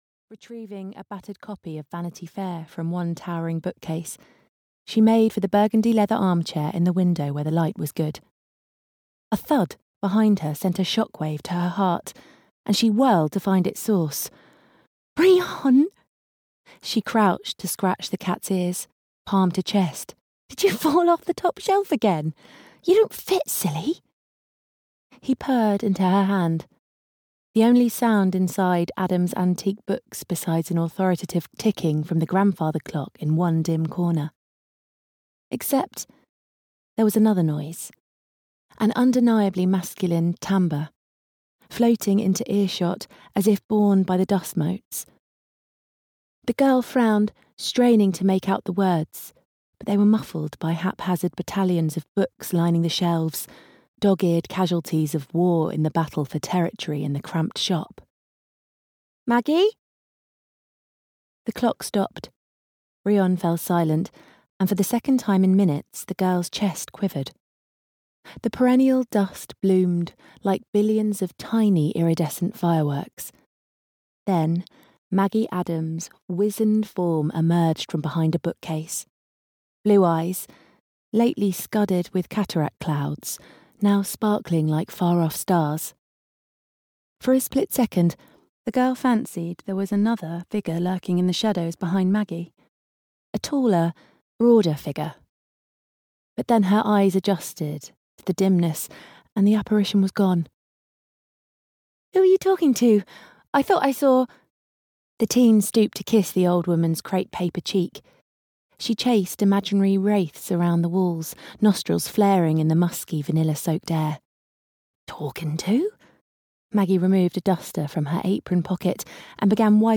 The Book Boyfriend (EN) audiokniha
Ukázka z knihy